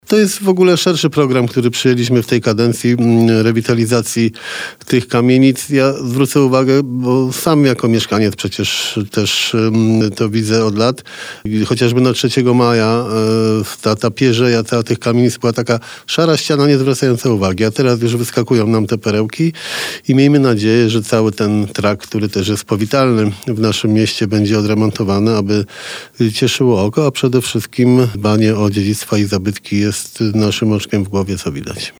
– W tej chwili rozpoczęły się [prace – red.] i są już rusztowania przy ul. 3 Maja 5, to jest tzw. budynek Fischera, który jest w jednym z centralnych miejsc w naszym mieście. Bardzo piękna kamienica, czego nie widać kiedy to jest szare i zaniedbane […]. Kolejna inwestycja to ul. Cieszyńska 23a, Pl. Wolności 10 […] i ul. Jana Sobieskiego 22 – wyliczał na naszej antenie Jarosław Klimaszewski, prezydent Bielska-Białej.